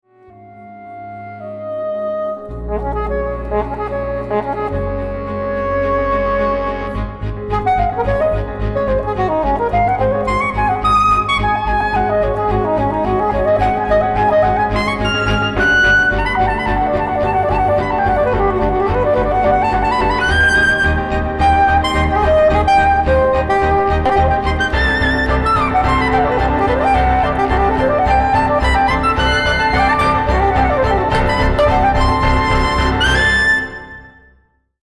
Blurs composer/performer lines and pushes genre boundaries